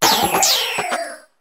arctibax_ambient.ogg